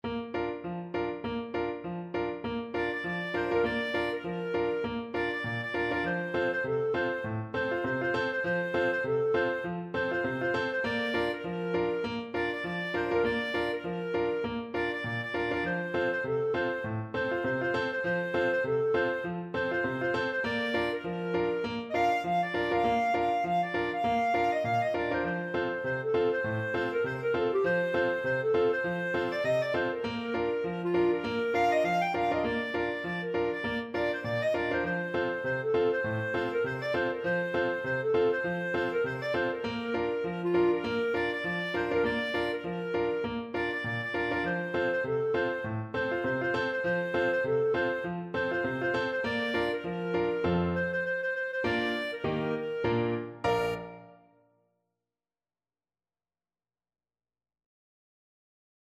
Clarinet
Traditional Music of unknown author.
2/2 (View more 2/2 Music)
F5-G6
Bb major (Sounding Pitch) C major (Clarinet in Bb) (View more Bb major Music for Clarinet )
Two in a bar with a light swing =c.100